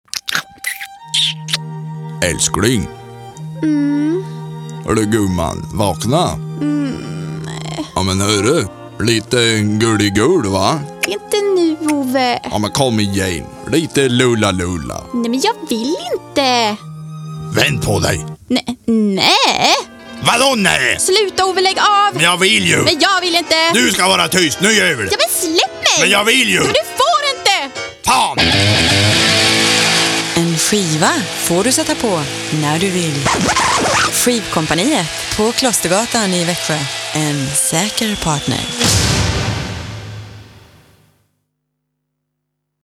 "Lullalulla" Lo-Fi